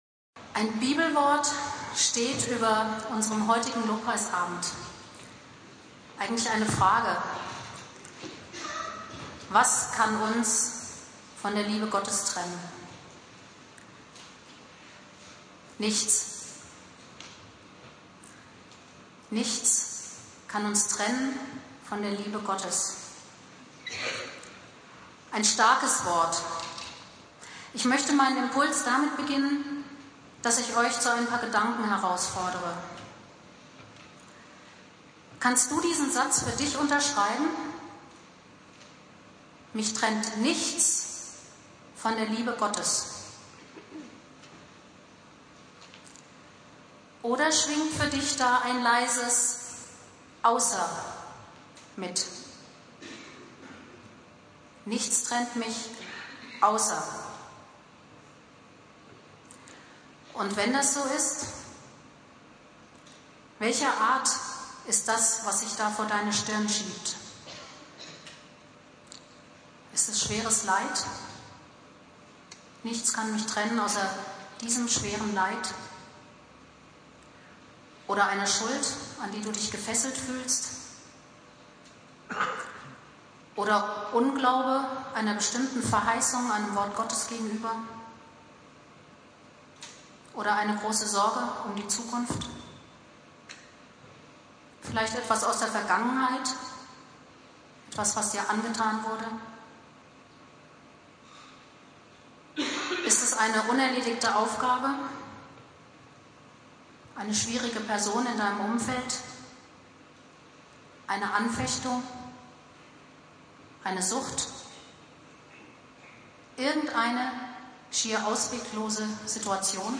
Predigt
Lobpreisabend "Bedingungslos geliebt" (im Bürgerhaus Hausen) Bibeltext: Römer 8,31-39 Dauer